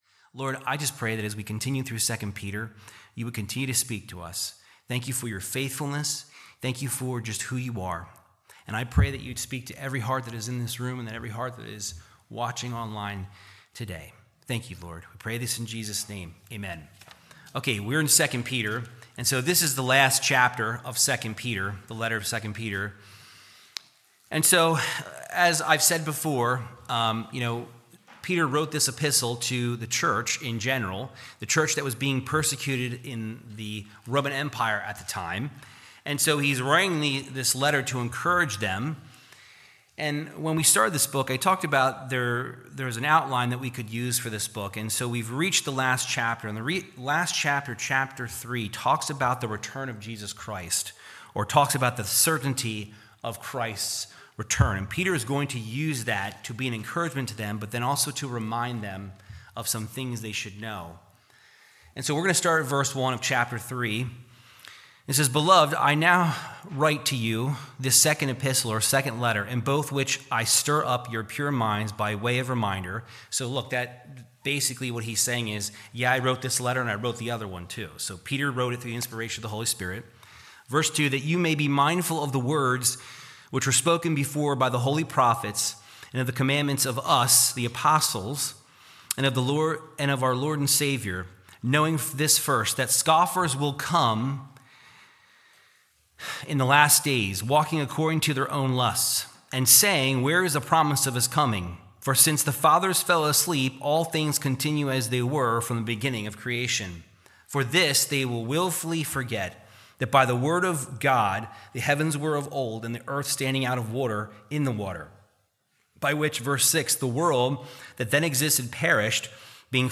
2 Peter 3:1-7 - Calvary Chapel Living Word - Church in Pottstown, PA
Verse by Verse Teaching of 2 Peter 3:1-7